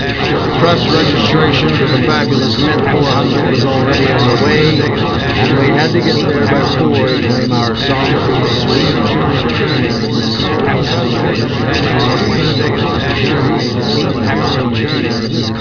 In the first, short speech recordings are obscured by a noise which is meant to resemble that in a crowded room with several people talking at the same time.
• In all examples, the speech consists of (slightly slurred) American English, spoken by a man
• All sound files are in the  .wav format (mono)
Part 1: Obscured speech